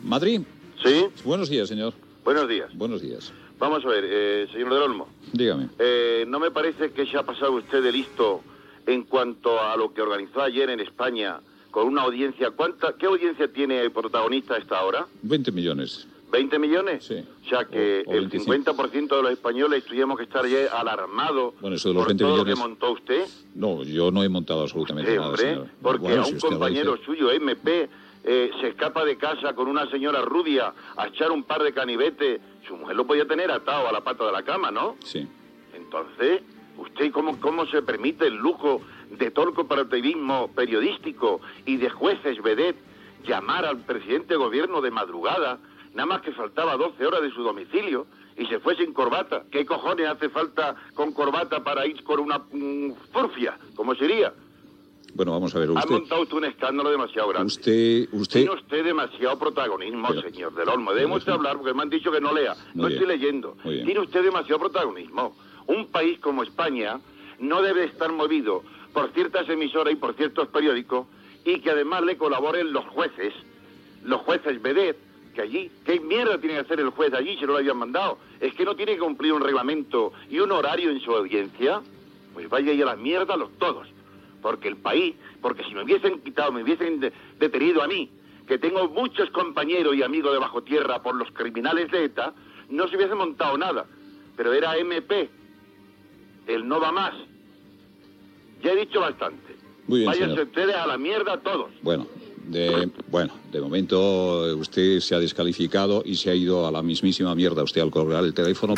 Intervenció d'un oient indignat amb el tractament de la falsa notícia donada el dia anterior.
Info-entreteniment